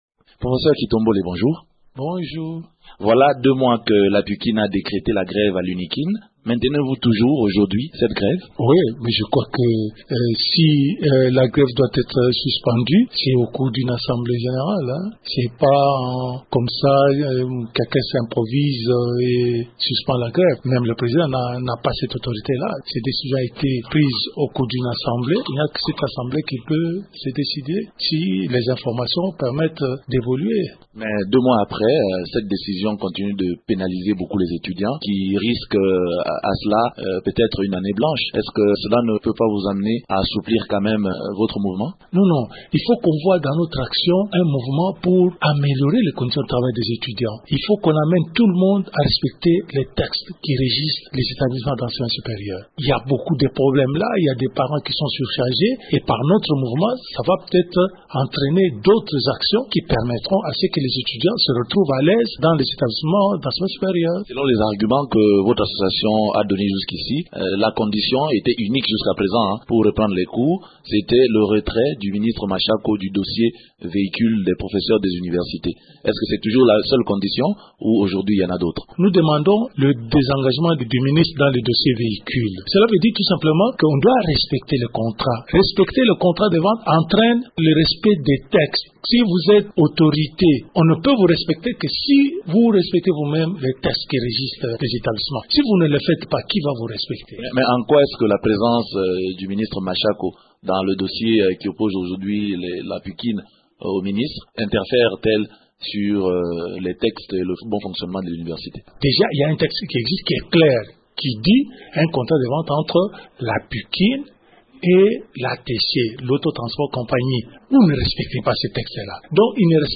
parle de cette grève au micro